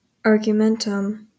Ääntäminen
Ääntäminen US Tuntematon aksentti: IPA : /ɑːɡjuːˈmɛntəm/ GA : IPA : /ɑɹɡjuˈmɛntəm/ Latinate: IPA : /ɑɹ.ɡuːˈmeɪn.tum/ Haettu sana löytyi näillä lähdekielillä: englanti Käännöksiä ei löytynyt valitulle kohdekielelle.